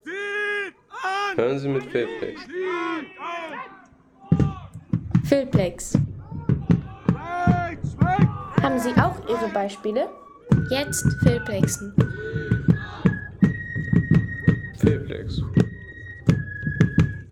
Nachgestellte militärische Führung einer Feldarmee – Tonaufnahme h ... 10,50 € Inkl. 19% MwSt.